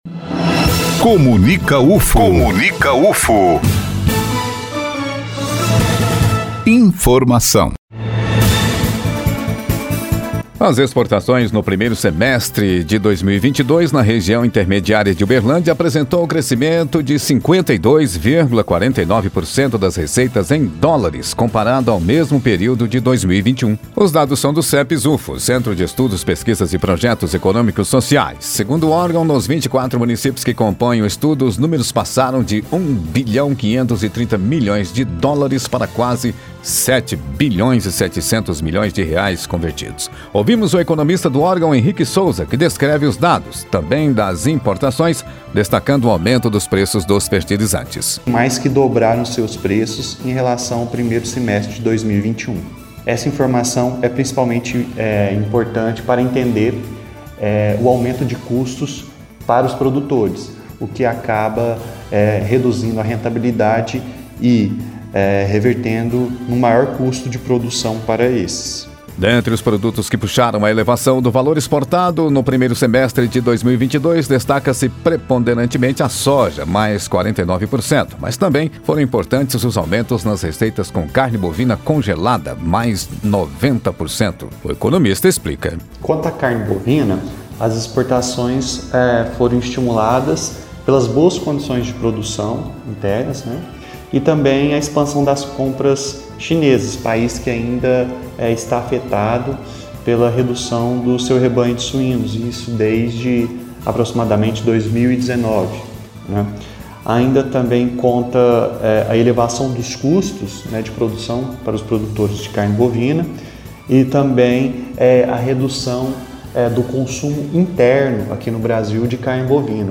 Entrevista concedida à Rádio Universitária - Agosto de 2022